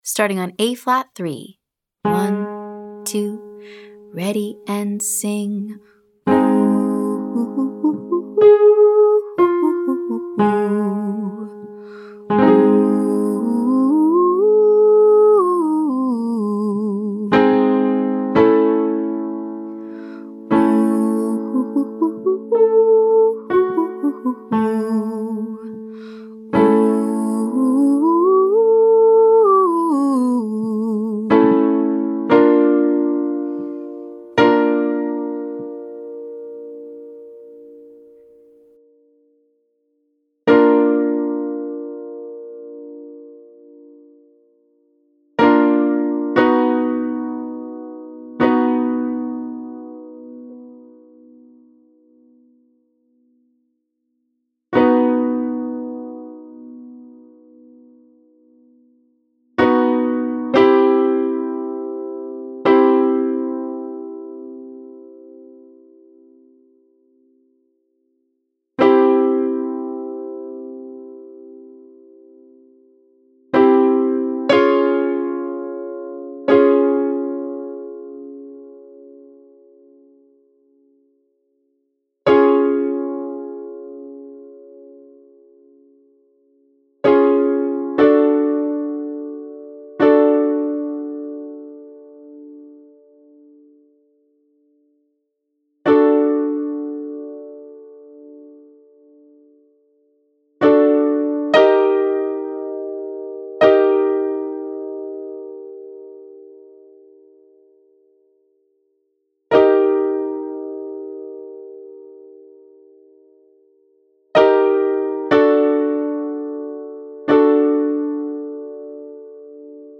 From: Daily High Voice Vocal Agility Warmup
2. Exercise 2: Ascending & descending pentatonic staccato, then legato.